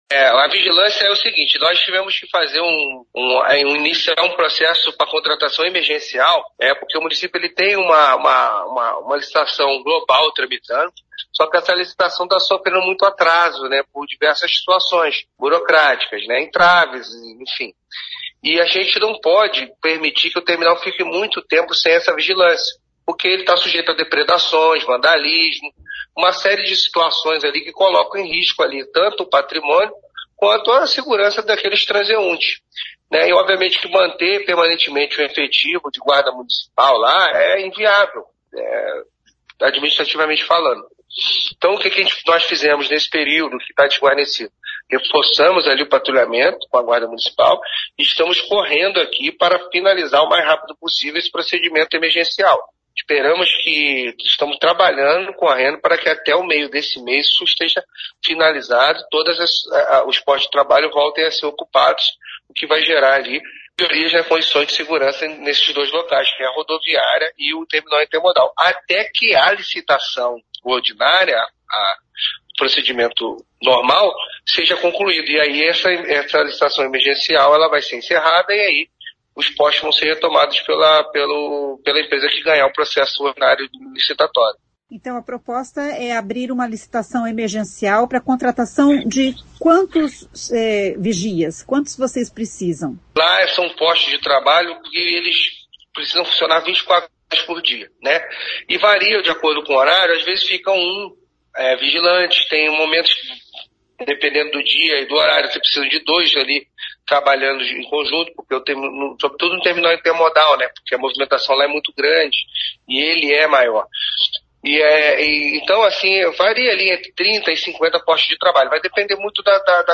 O secretário Luiz Alves explica que a contratação é emergencial porque o processo licitatório global está demorando mais do que o esperado.